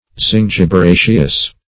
zingiberaceous.mp3